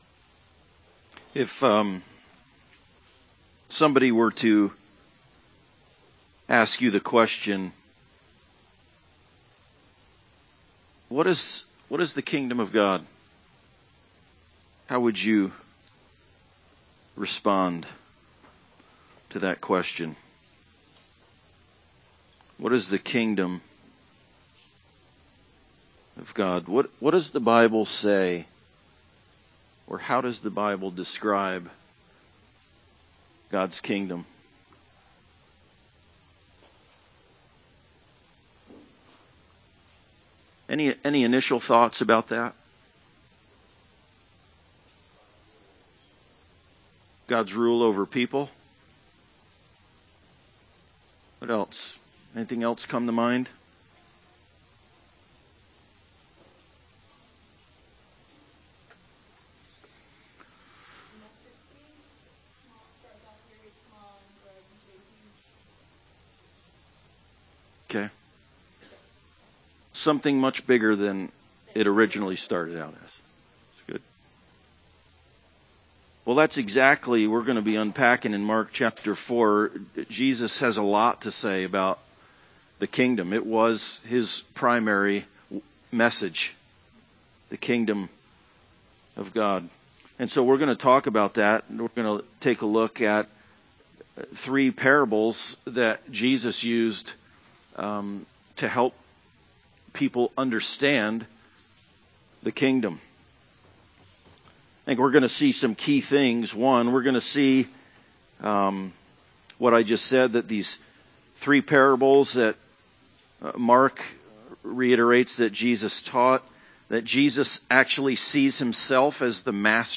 Passage: Mark 4:1-32 Service Type: Sunday Service